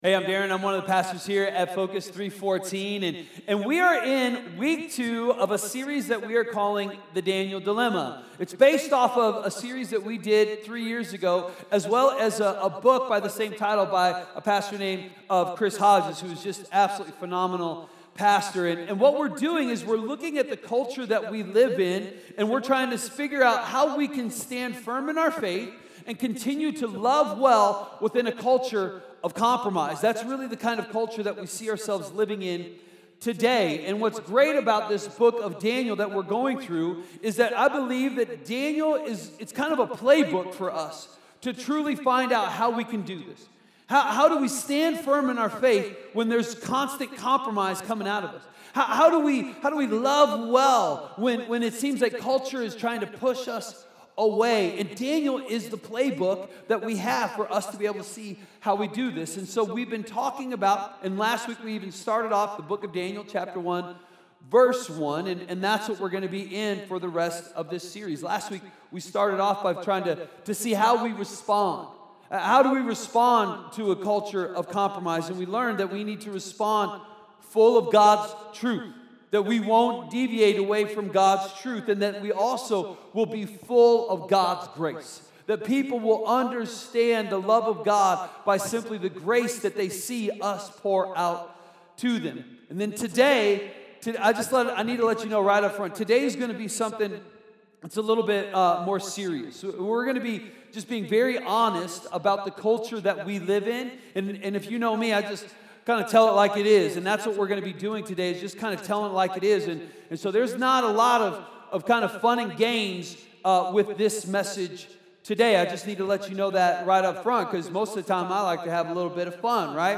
A message from the series "The Daniel Dilemma." How do you hold on to God's truth in a culture of compromise?